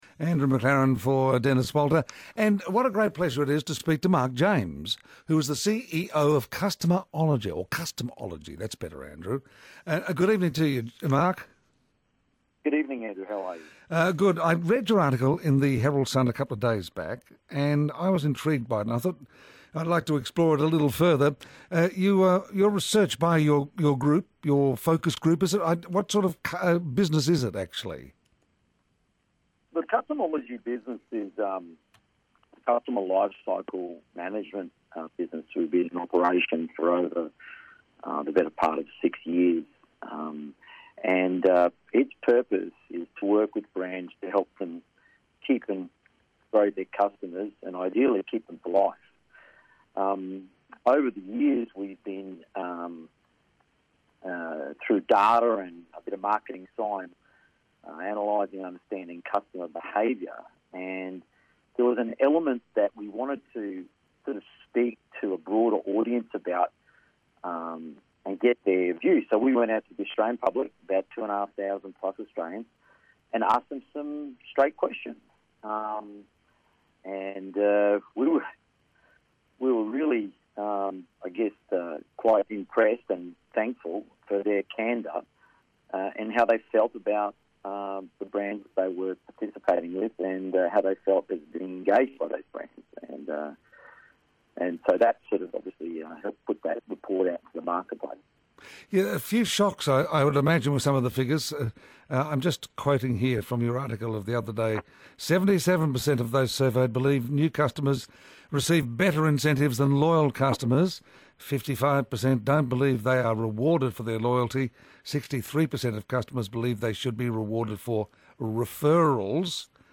He took several calls from listeners, responding to their questions and frustrations about their own experiences. He also shared several personal stories of his own.